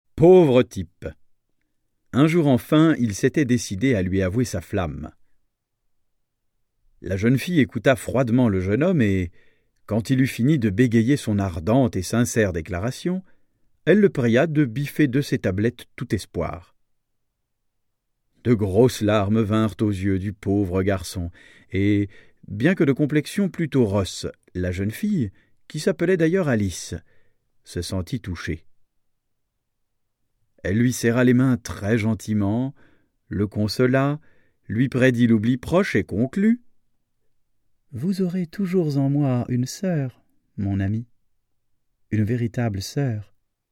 Extrait gratuit - Mieux qu’une sœur de Alphonse Allais